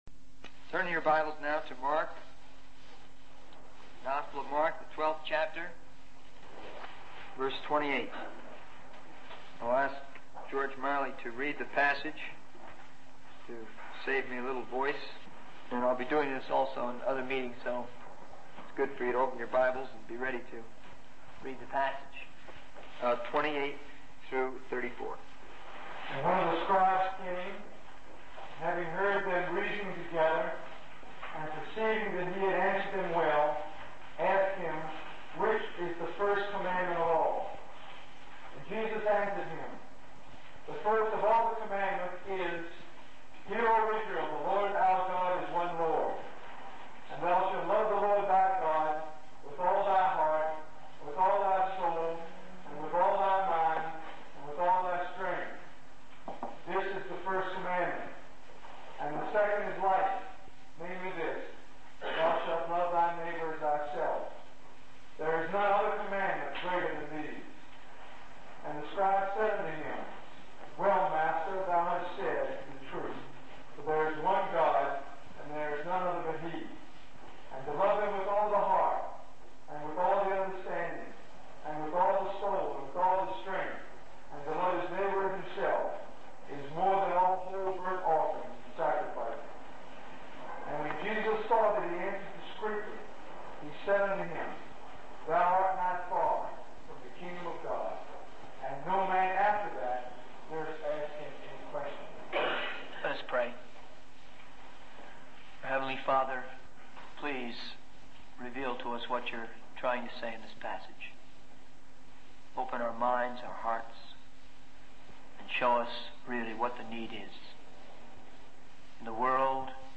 In this sermon, the preacher emphasizes the importance of love as the answer to the world's problems. He mentions various crises such as the Jewish crisis, the Vietnam crisis, and the issue of racism. The preacher believes that love, when manifested through a change of heart, can bring about a revolution.